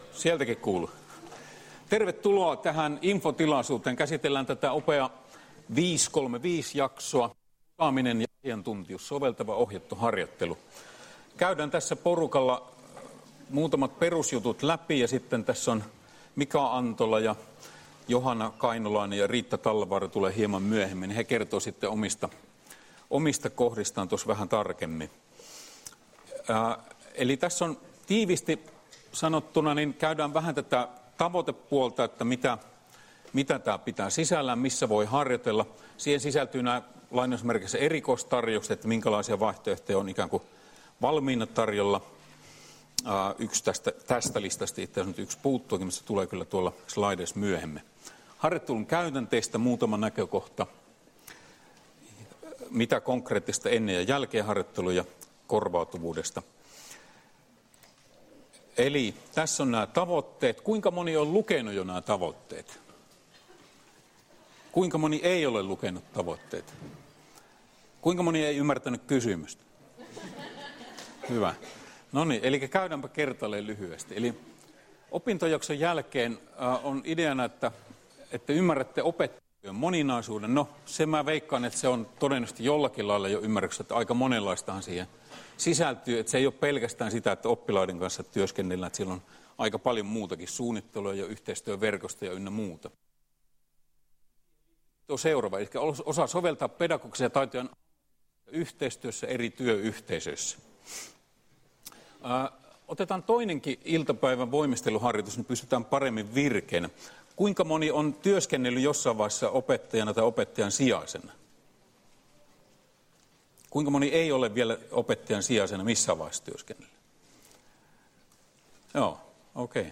Luento 28.9.2017 — Moniviestin